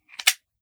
38 SPL Revolver - Open Barrel 002.wav